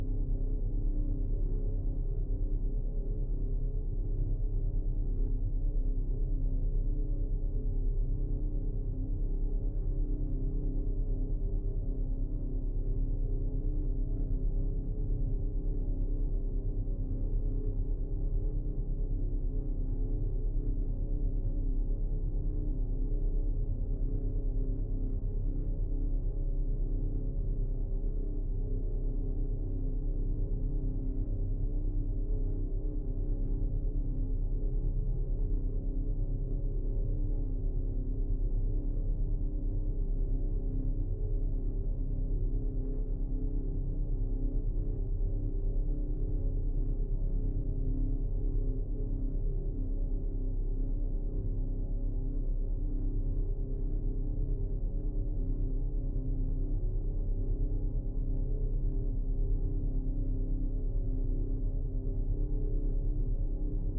Sci-Fi Sounds / Hum and Ambience
Low Rumble Loop 4.wav